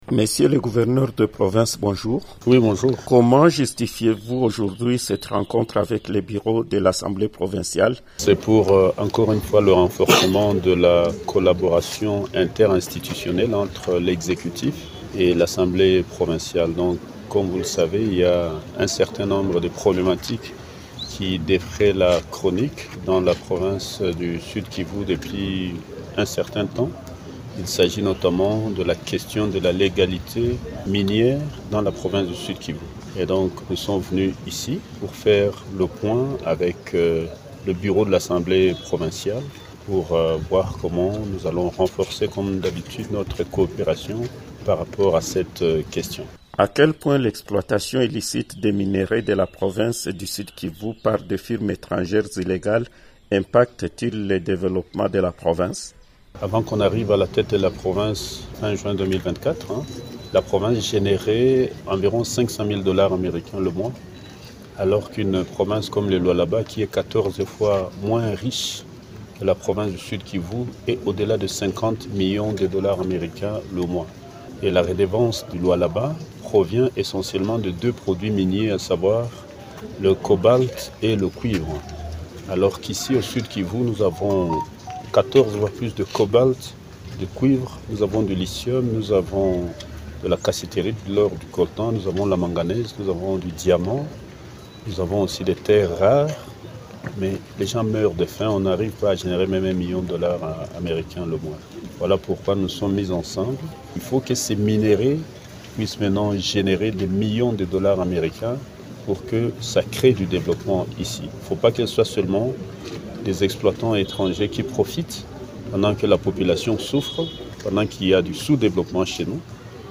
Il a fait cette déclaration au cours d’une interview à Radio Okapi.